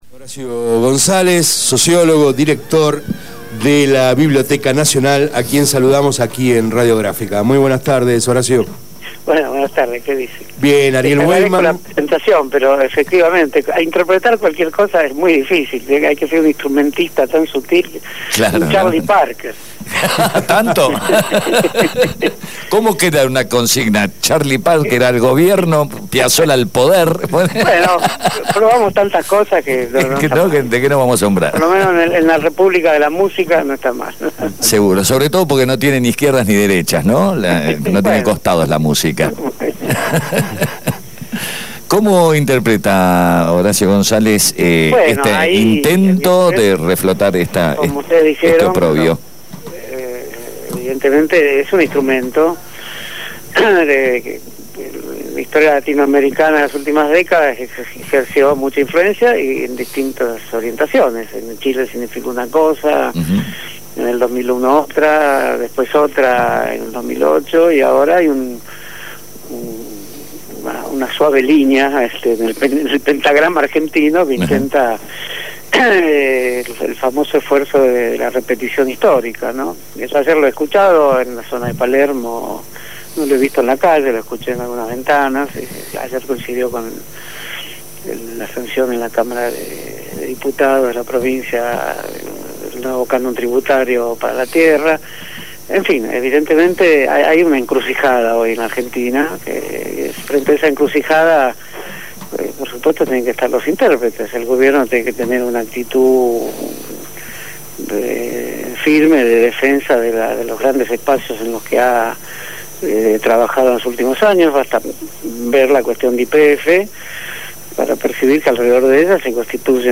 Entrevista a Horacio González, director de la Biblioteca Nacional, quien habló en Abramos la Boca